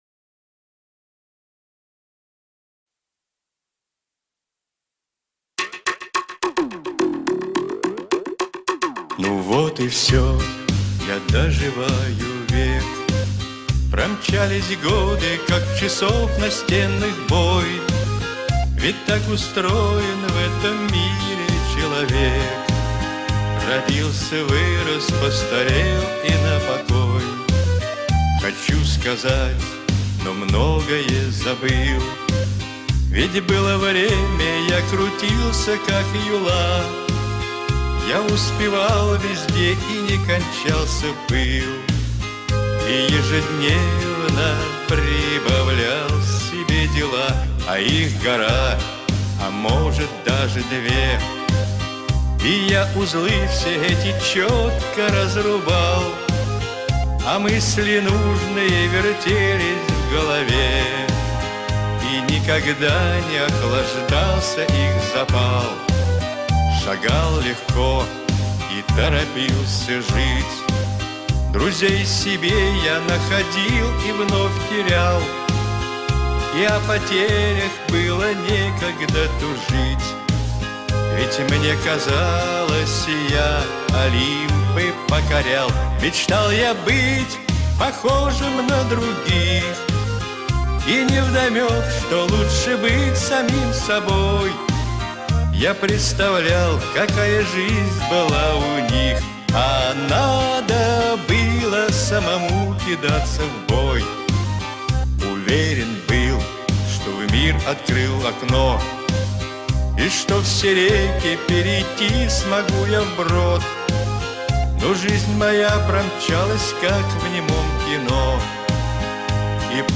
Очень приличная и мелодичная, не то что исполняют на наших попсовых станциях.